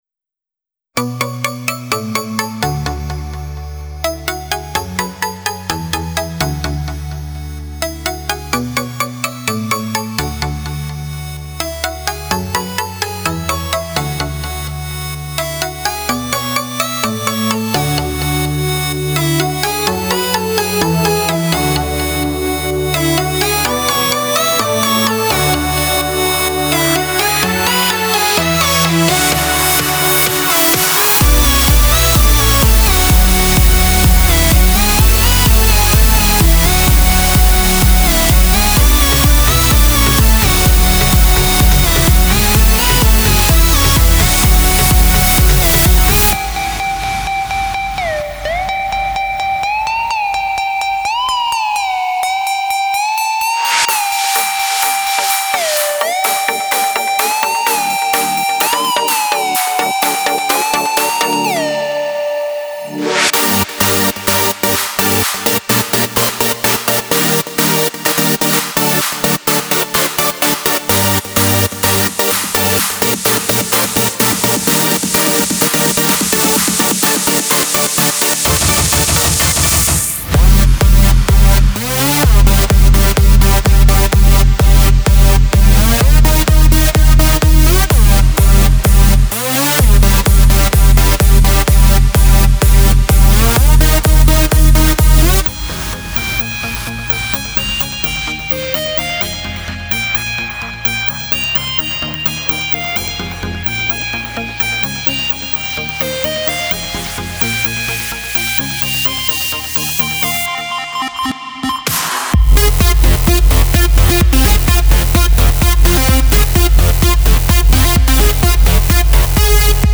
soundbank for reveal sound spire synthesizer
Loaded with 90 powerful EDM sounds for the Spire synthesizer that took the EDM producer scene by storm.
Then listen to the demo track, which shows 68 of the 90 contained sounds and hear what is possible with this exclusive soundset for Reveal Sound Spire!
Content in detail: 20 Basses, 6 FX Sounds, 19 Leads, 9 Pads, 11 Plucks, 5 Sequences and 20 Synth sounds – 90 Spire presets in total!
CFA-Sound - Spire Evolution Vol.1 Soundset.mp3